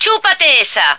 flak_m/sounds/female2/est/F2eatthat.ogg at trunk